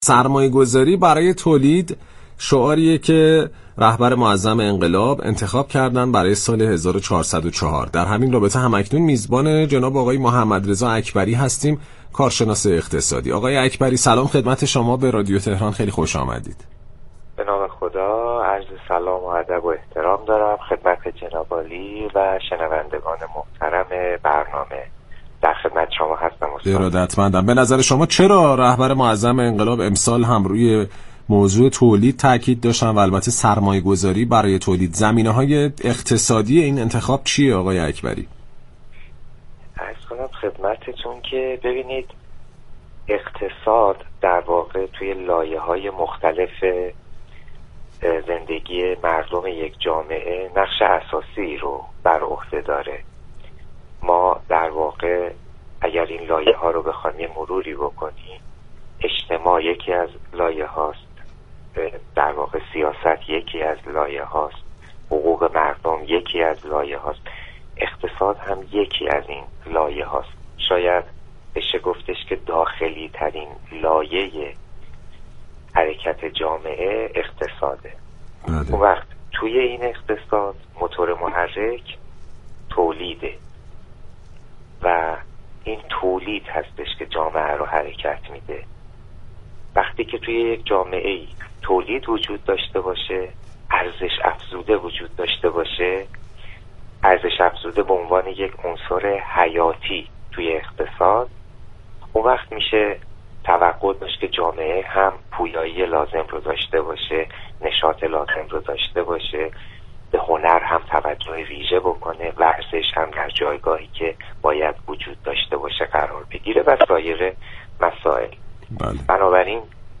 یك اقتصاددان در گفت‌و‌گو با رادیو تهران گفت: بسیاری از كشورهای دنیا تلاش می‌كنند قیمت پول ملی خود را ثابت و یا پایین نگه دارند تا به حوزه تولیدشان صدمه‌ای وارد نشود. این درحالی است كه نرخ ارز در ایران همواره نوسان دارد.